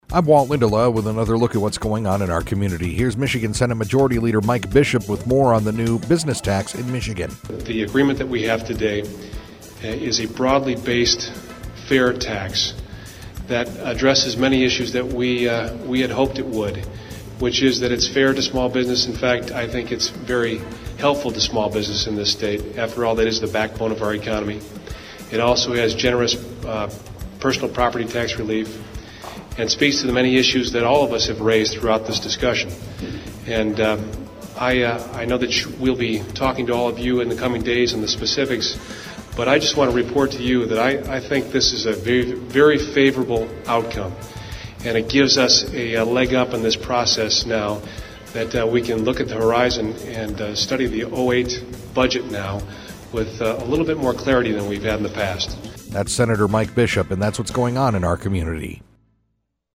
INTERVIEW: State Senate Majority Leader Mike Bishop